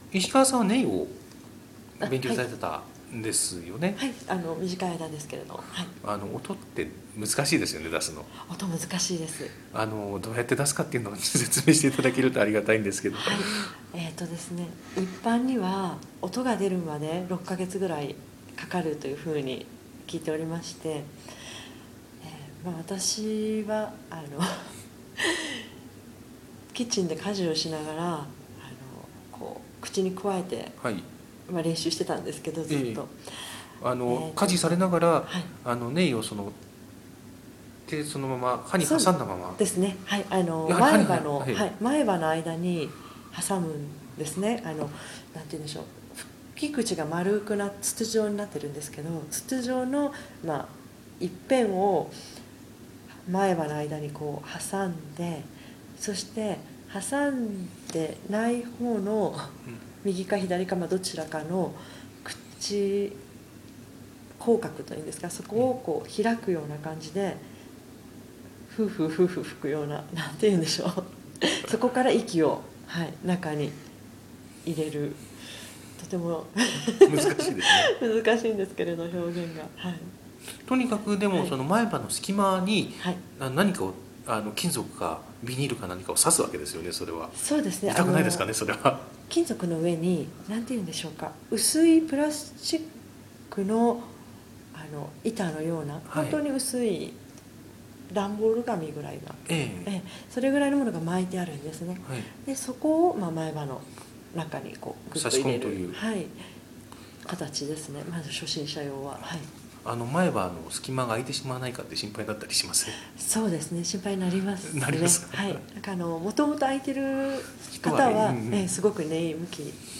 日本人クラリネット奏者へのインタビュー